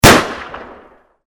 gunshot.mp3